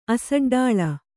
♪ asaḍḍāḷa